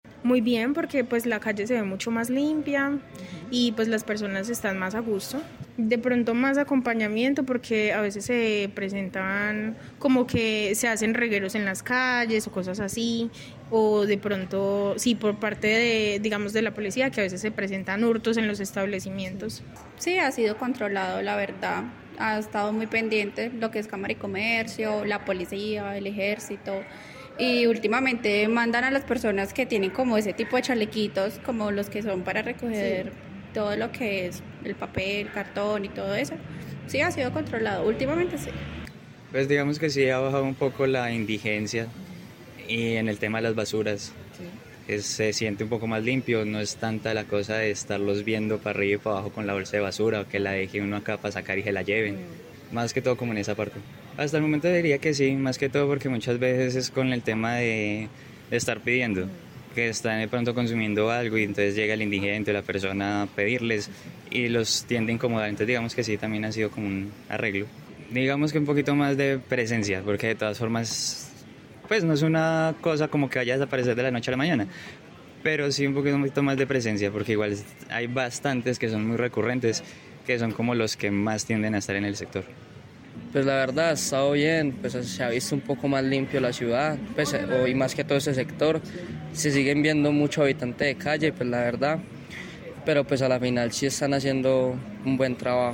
Informe comerciantes de la 14 de Arnenia
Caracol Radio Armenia salió por la carrera 14 de la ciudad a consultar las opiniones de los comerciantes, como Insomnia Coffee, Croydon, Marantis y Bata, tras la intervención realizada por la Cámara de Comercio en conjunto con la Alcaldía para mejorar la imagen de este sector peatonal.